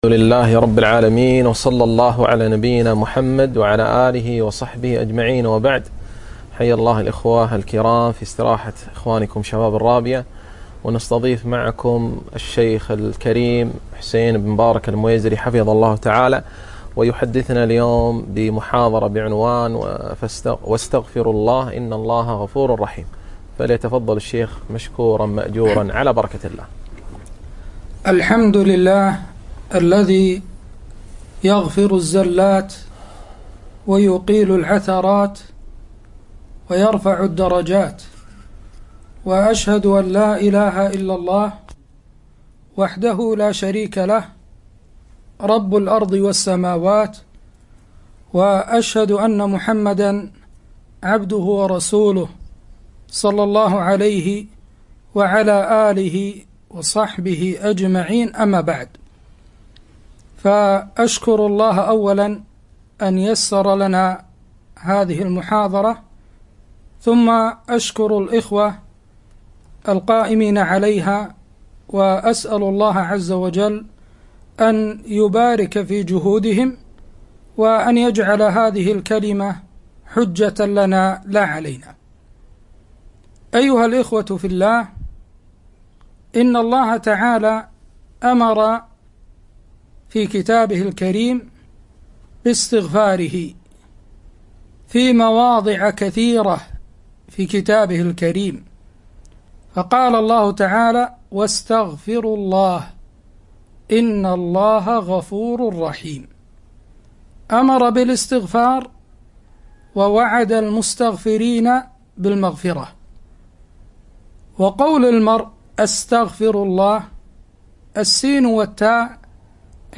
محاضرة - (واستغفروا الله إن الله غفور رحيم)-